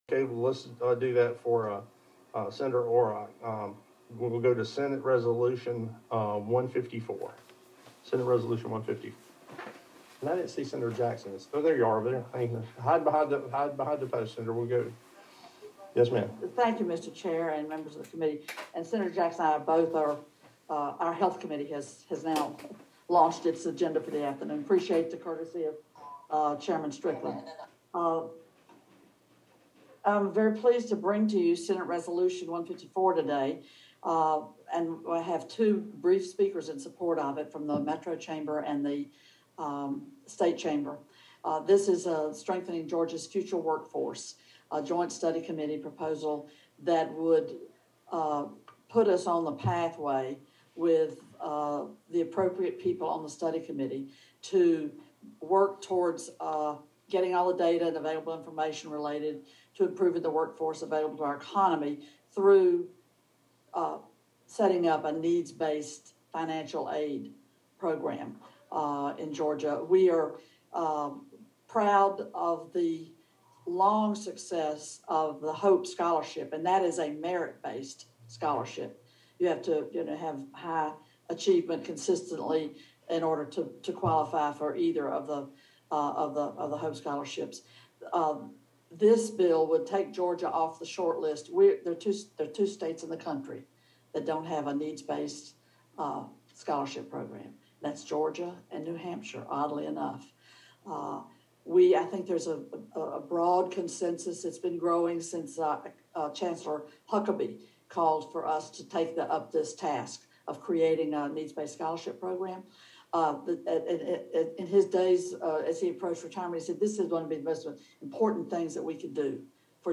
Nan Orrock SR 154 hearing Mar 17, 2021 AUDIO for transcript.